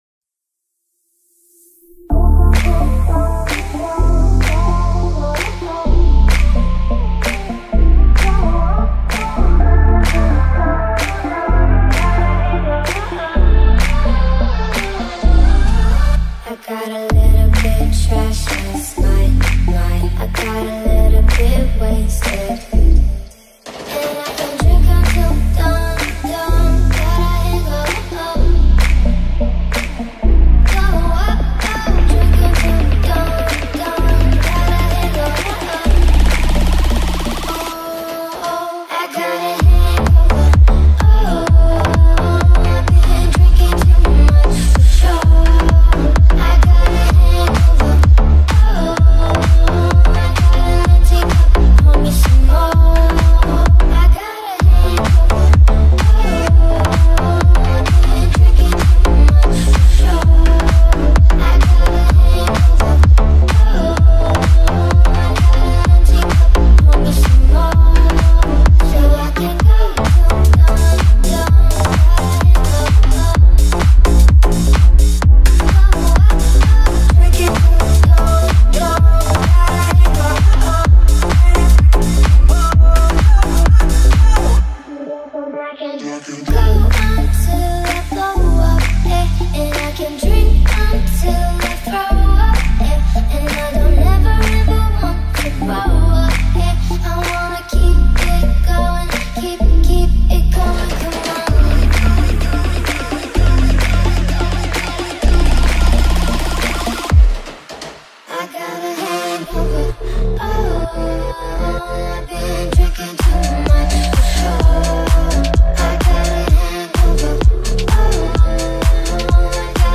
دانلود آهنگ بوم بوم بدنسازی